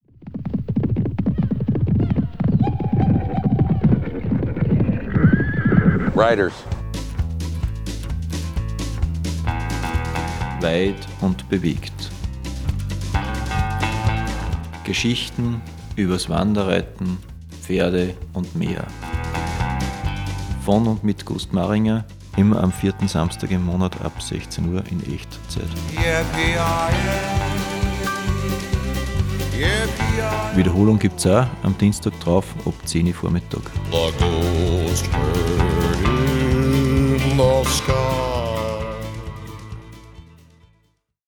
Sendungstrailer
FRS-TRAILER-WEIT-UND-BEWEGT-4-SAMSTAG.mp3